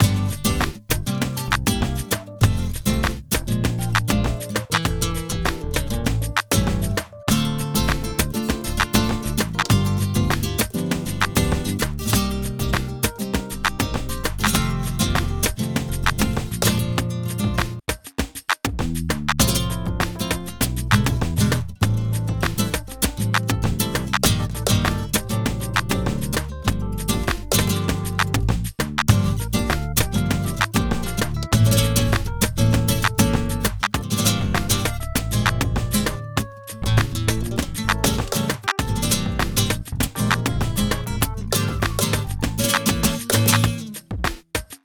Rumba flamenco (bucle)
flamenco
melodía
repetitivo
rítmico
rumba